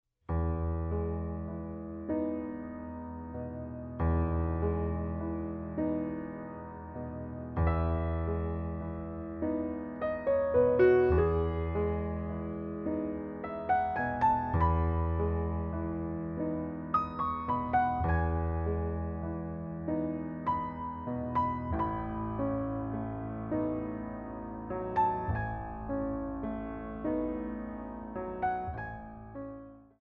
Original Music for Ballet Class
Recorded on a Steinway B at Soundscape
4 Count introduction included for all selections
3/4 - 96 with repeat